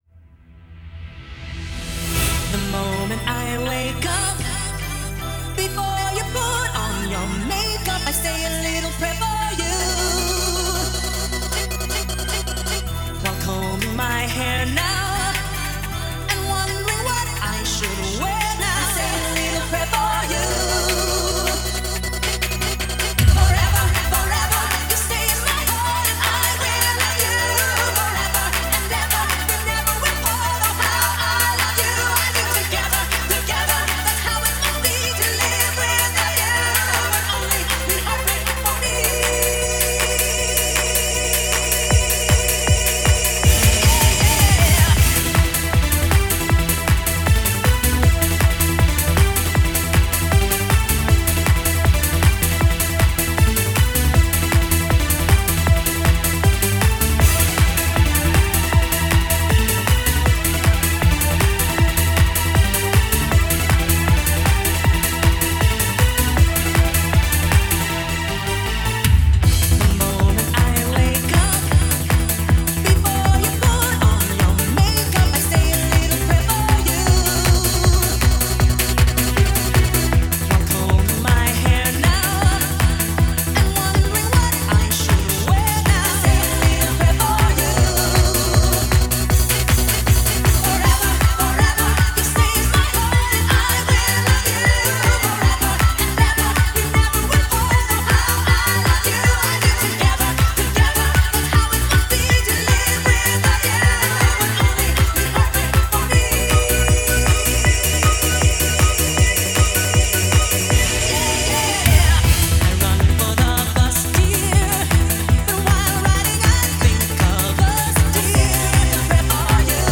Genre: Happy Hardcore.